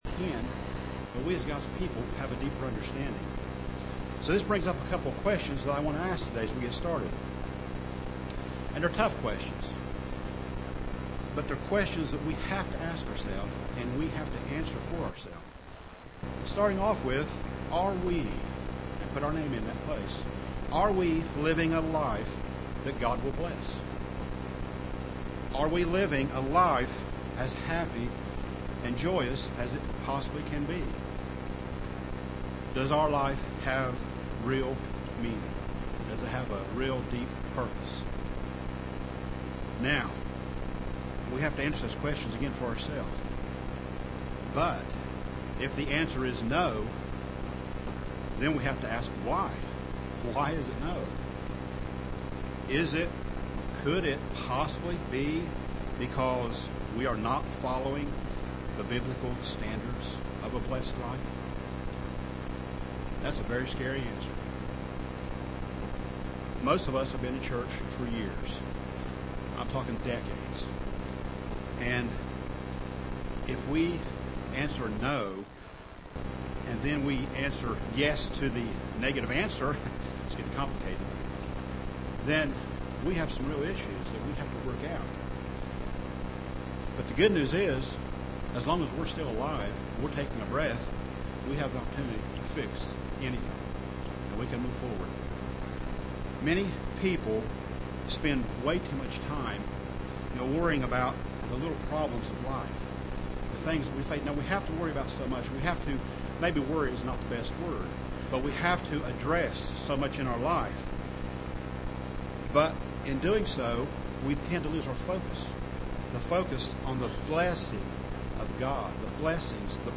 Given in Portsmouth, OH
UCG Sermon Studying the bible?